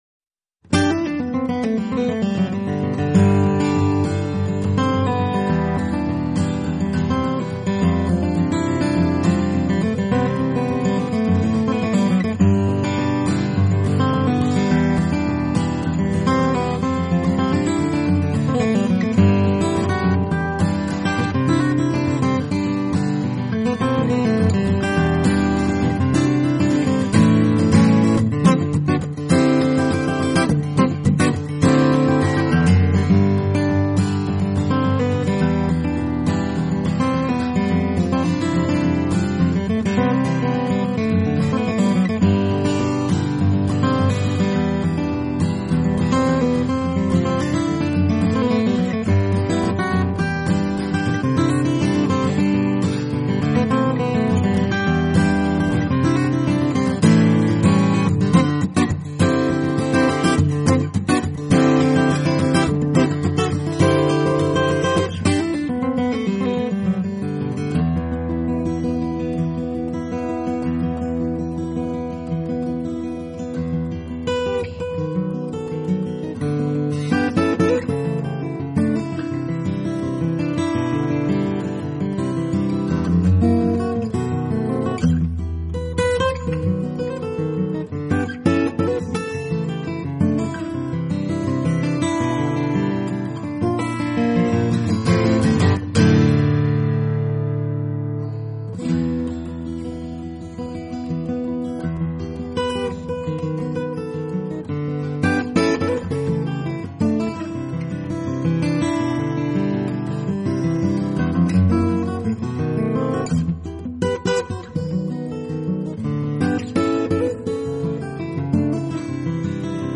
【吉他专辑】
音乐流派：Country, Pop, Miscellaneous, Jazz